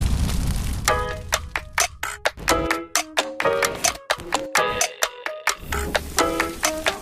LSL_130_latin_piano_the_knives_Am
LSL_130_latin_perc_loop_marvin_top